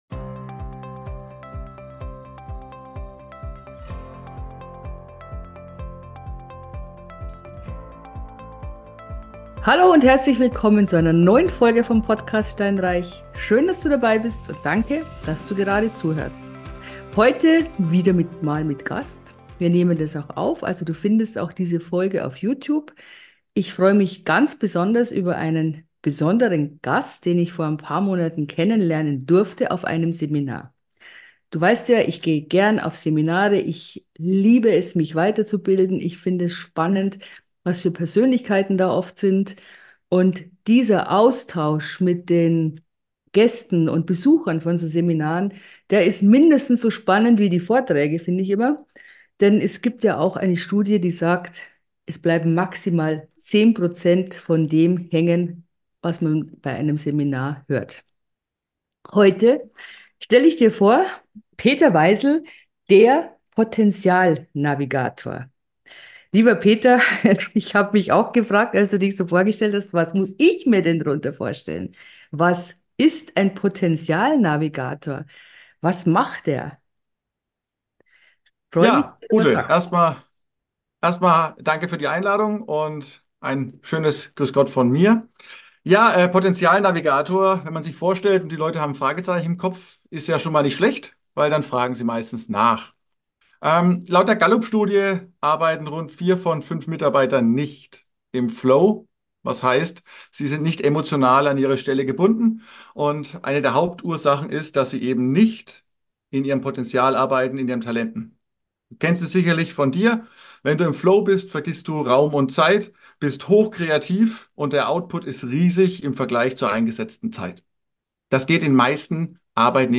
Interview mit dem Potential Navigator ~ Steinreich - Vermögensaufbau mit Immobilien Podcast